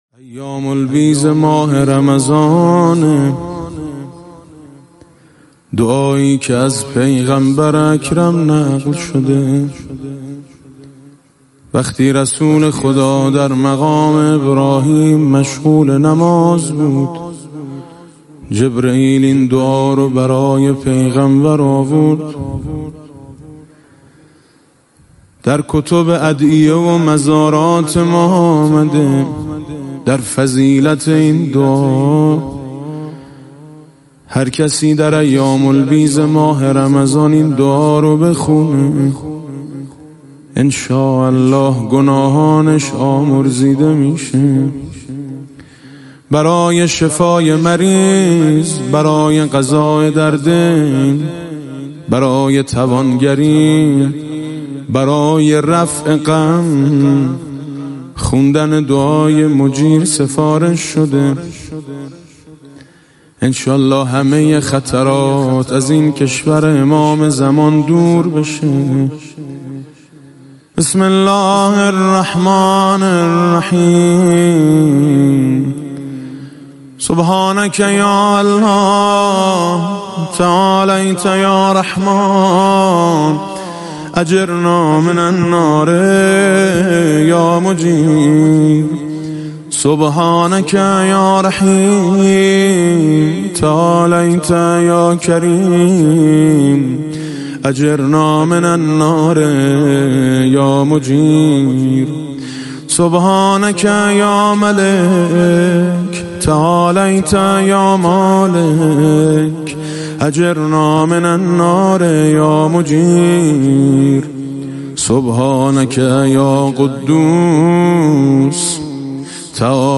دعای مجیر با نوای میثم مطیعی | سایت جامع ماه مبارک رمضان